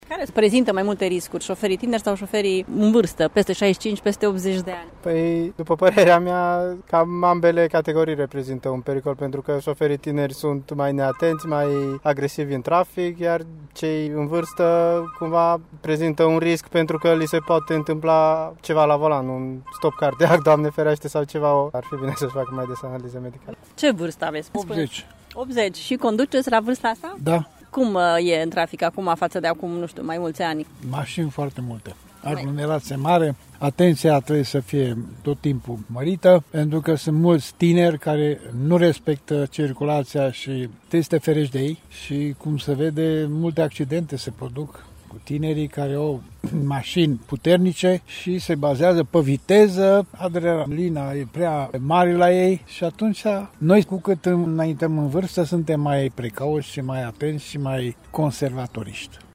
Târgumureșenii cred că atât șoferii vârstnici, cât și cei foarte tineri prezintă un risc mai mare pentru trafic: